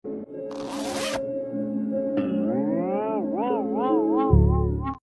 zippp boing boing boing sound effects free download